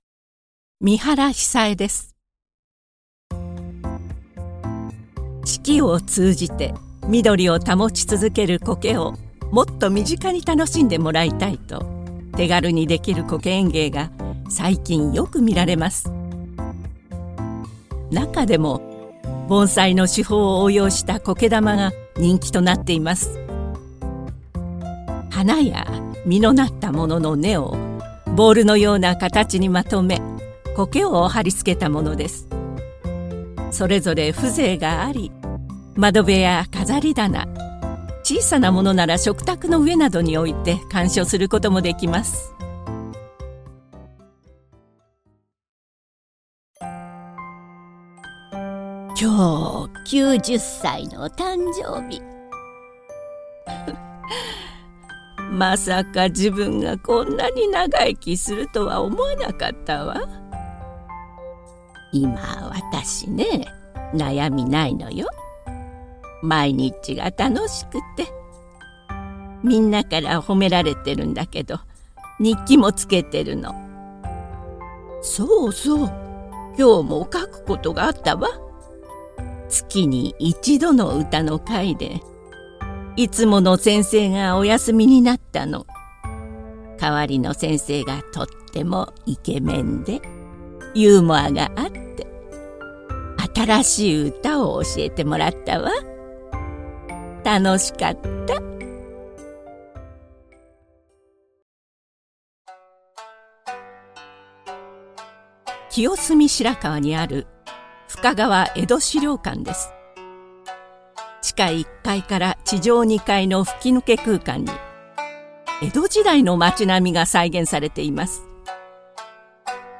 落ち着いた語り〜庶民的なおばあちゃんの声